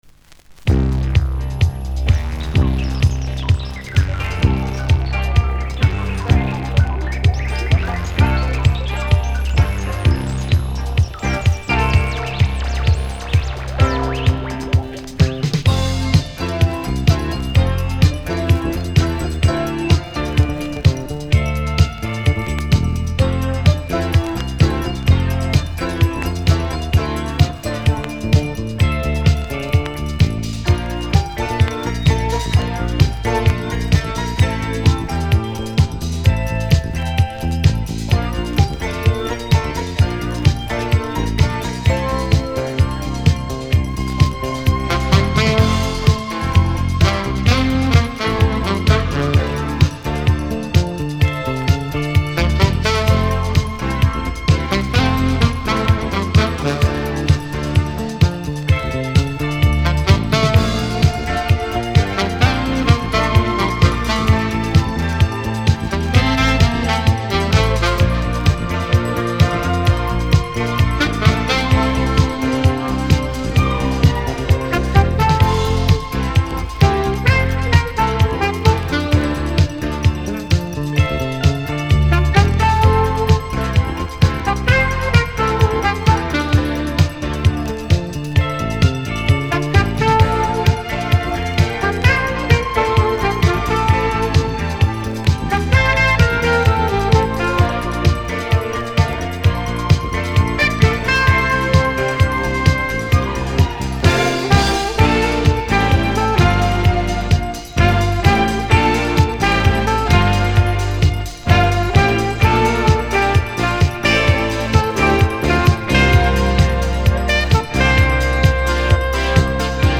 здесь в диско стиле